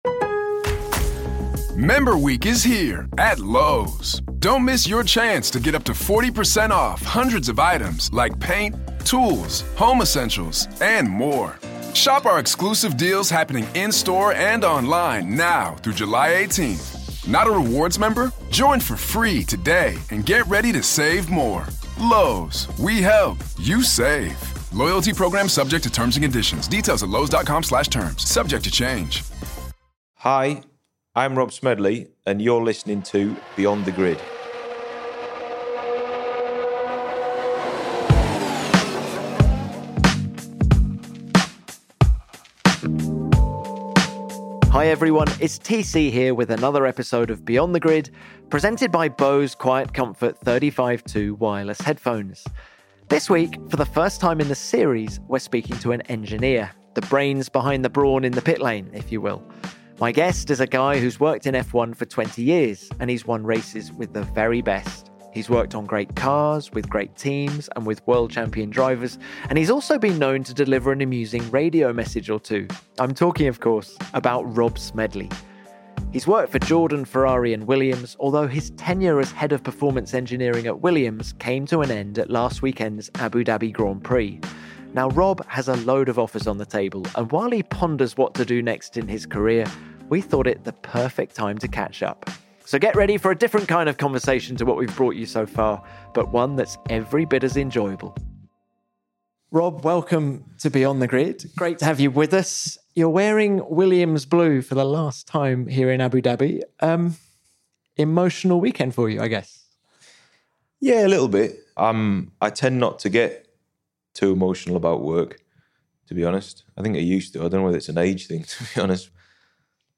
This week, for the first time on Beyond The Grid, presented by Bose, we’re not speaking to a driver or a team boss, but to an engineer – and someone who’s been directly involved of some of the biggest moments in F1 in the past 20 years.